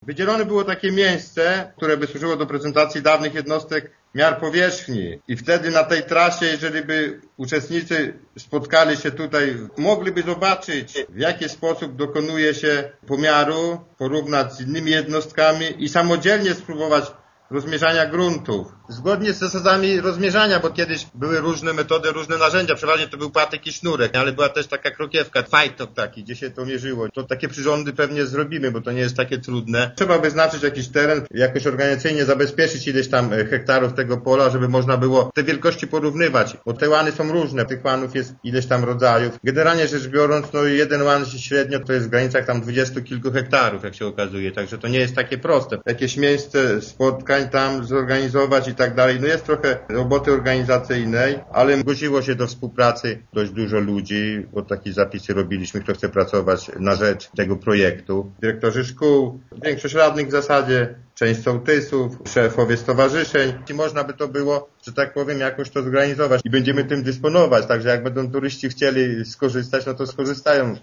Wstępny zarys projektu jest już przygotowany – informuje wójt gminy Gościeradów Jan Filipczak: